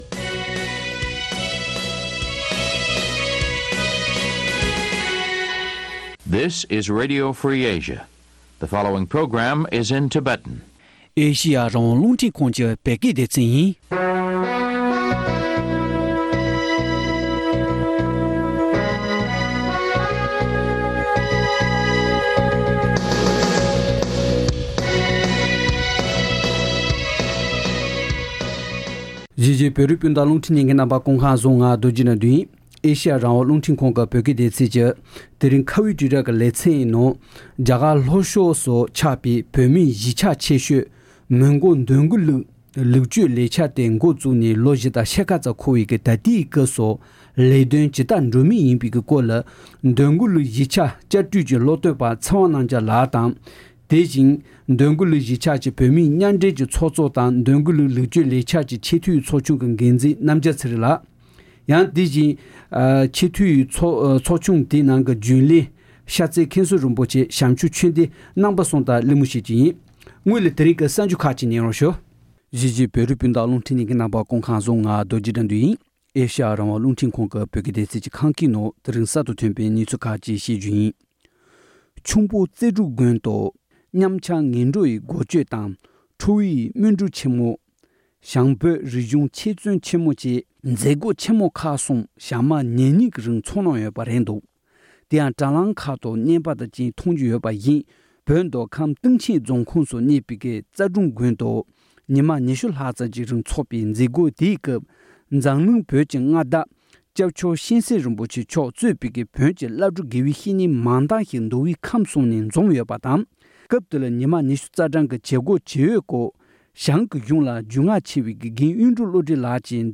བཅའ་འདྲི་བྱས་པ་གསན་རོགས